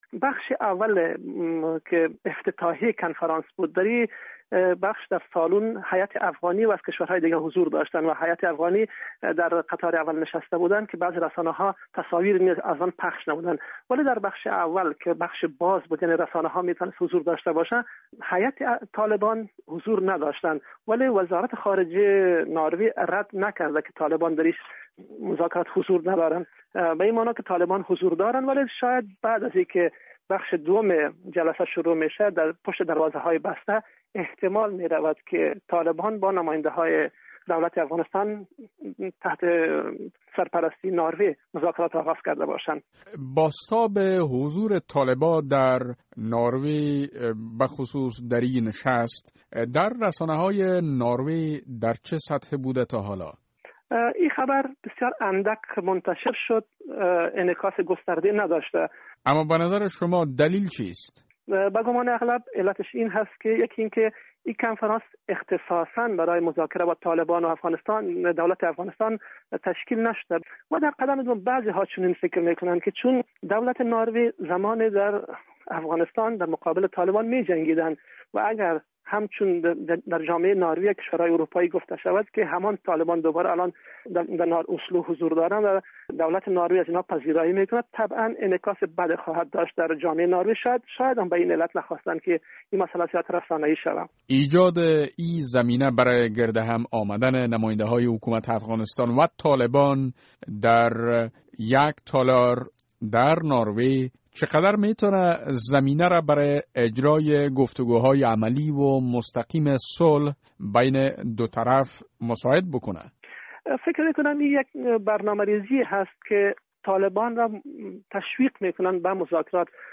مصاحبه در مورد شرکت هیئت حکومت افغان و طالبان در مجمع اسلو